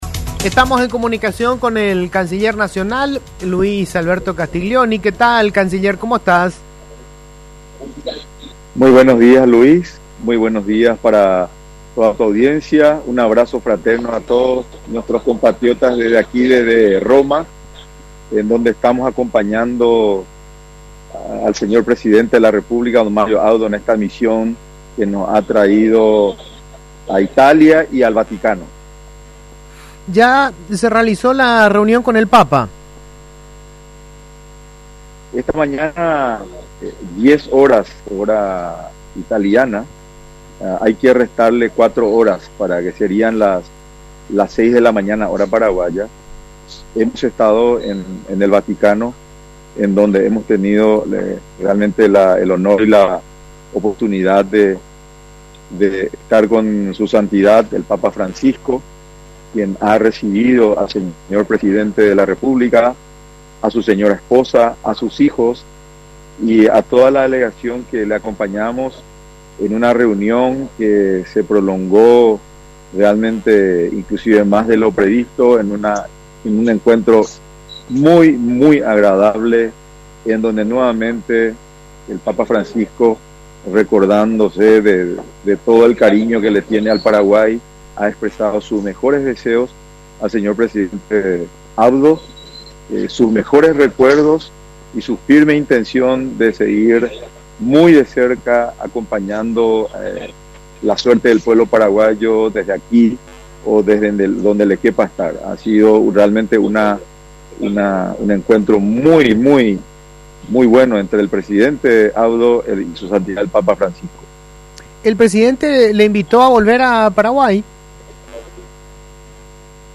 El presidente italiano, Sergio Mattarella, aceptó la invitación oficial de Mario Abdo Benítez para que visite el país, anunció a Radio Monumental el canciller nacional Luis Castiglioni.